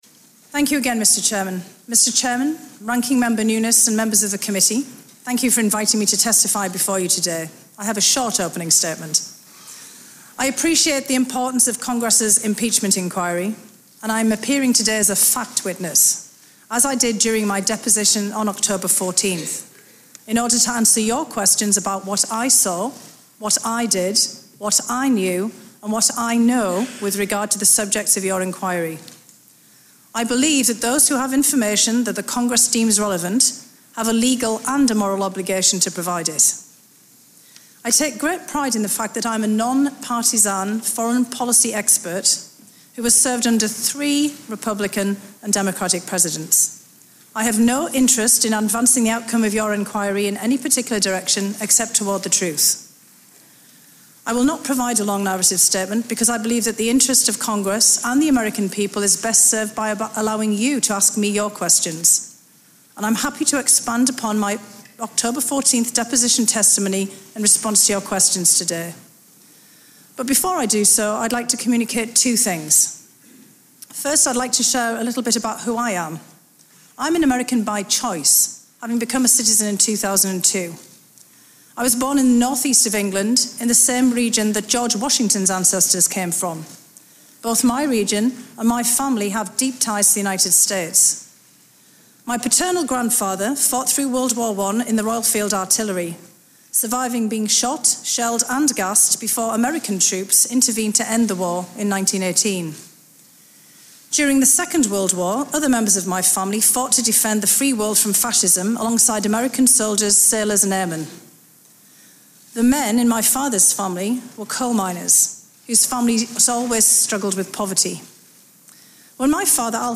Opening Statement to the House Permanent Select Committee on Intelligence
delivered 21 November 2019, Washington, D.C.
fionahillopeningstatementimpeachmenthearingARXE.mp3